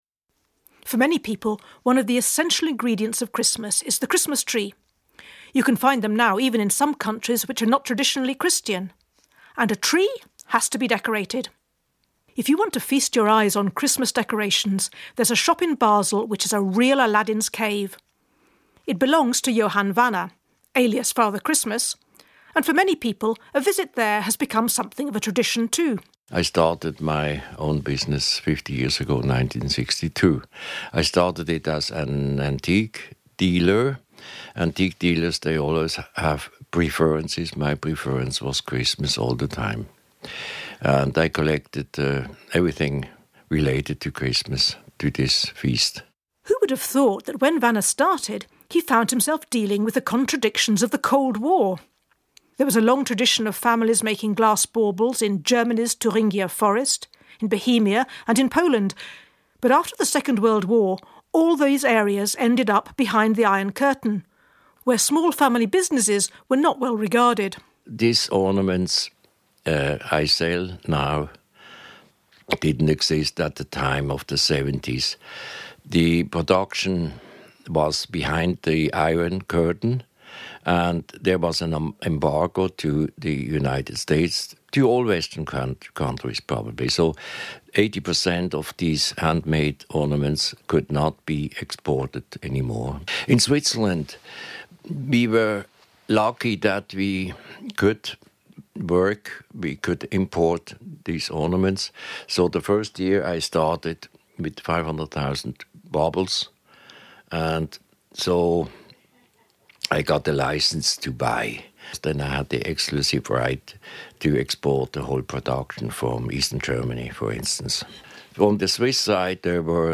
visits a shop in Basel where it's Christmas all year round.